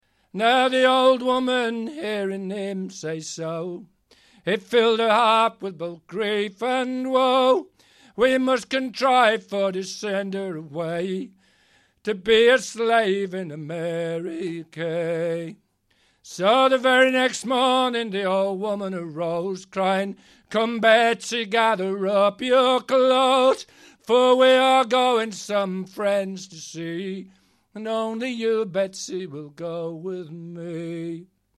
Ashington Folk Club - 01 February 2007
a song with a very sad tale